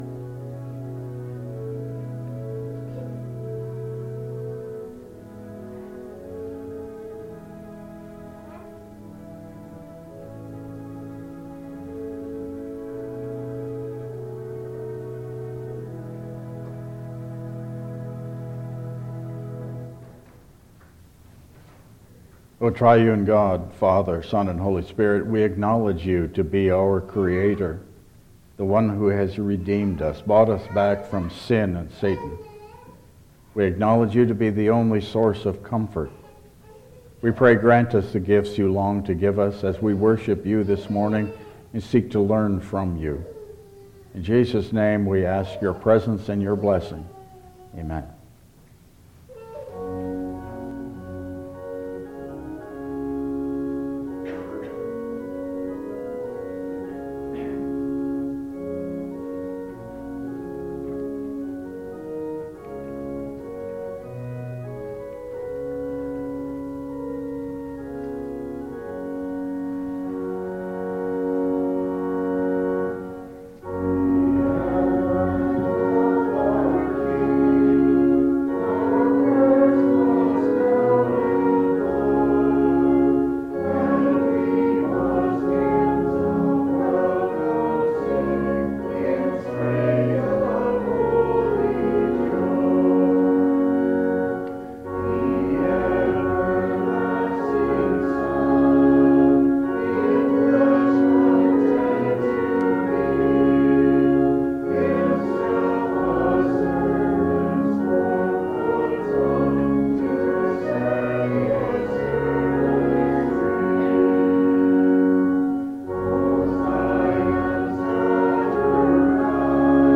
Passage: Matthew 11:2-11 Service Type: Regular Service